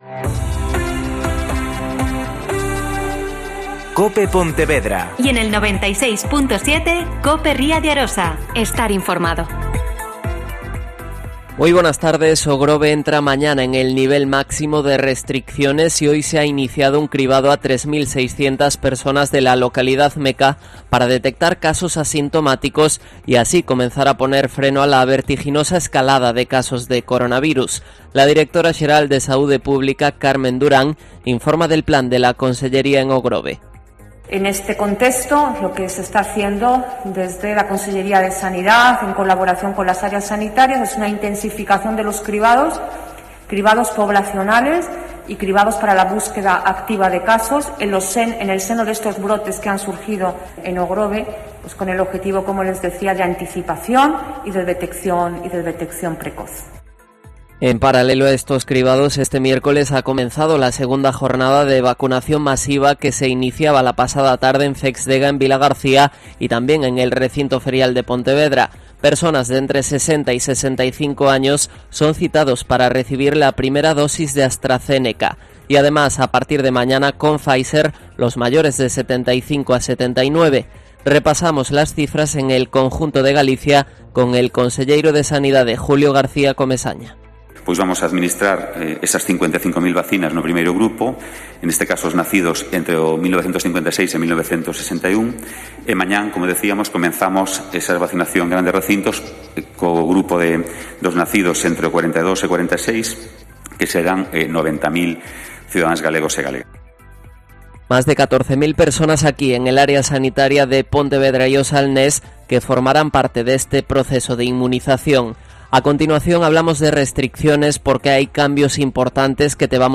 Mediodía COPE Ponteverda y COPE Ría de Arosa (Informativo 14:20h)